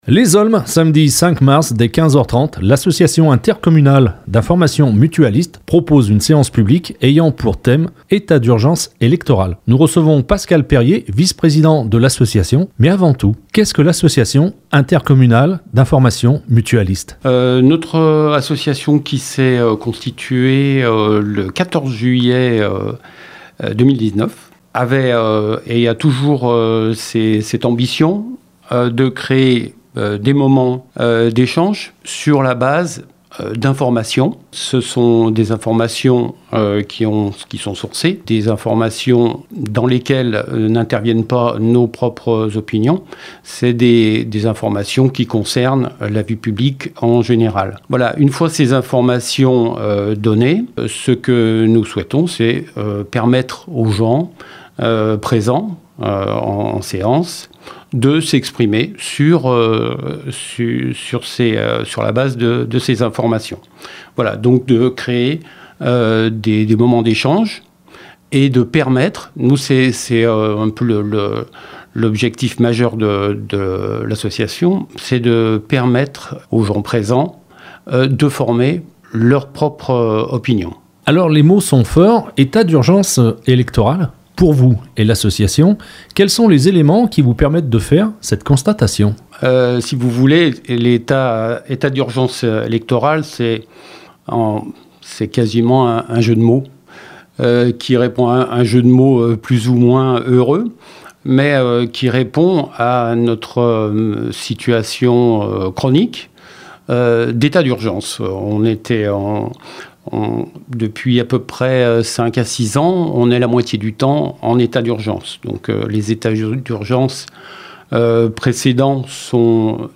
séance publique AIIM 05 mars 2022